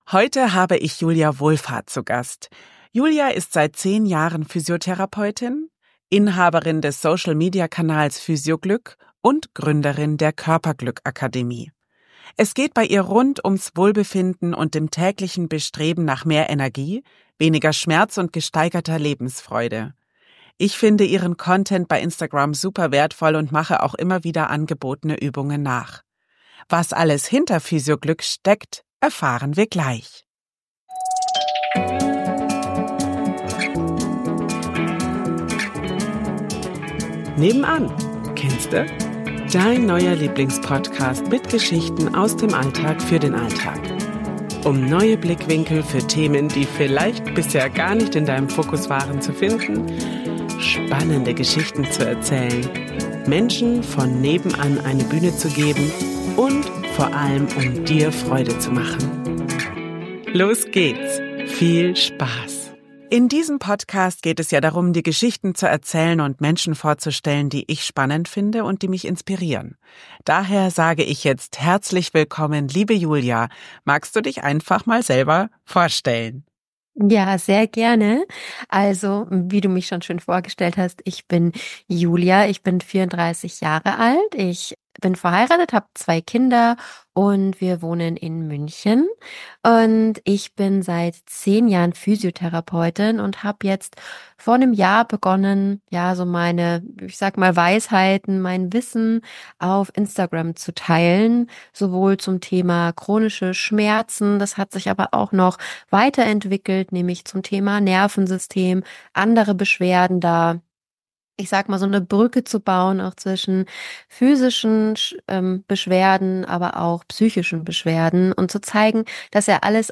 72. Physioglück - ein Interview